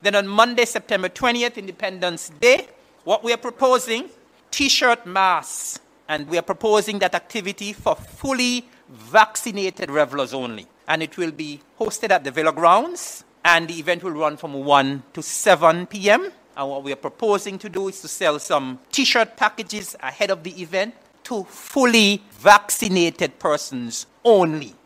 Nevis’ Minister of Culture, Eric Evelyn, gave this notice of a live event for fully vaccinated patrons of Culturama 47: